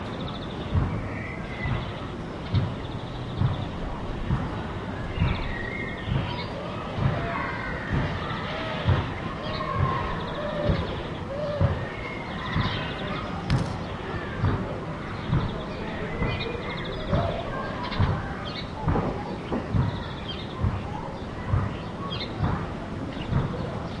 环境 " 户外环境 蝉
描述：夏季户外氛围由蝉主导。还有微风。代表新西兰奥克兰的典型夏日。用Zoom H6录制。
Tag: 花园 新西兰 氛围 环境 微风 奥克兰 氛围 昆虫 性质 现场录音 户外 夏天